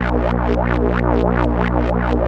3100 AP  A#2.wav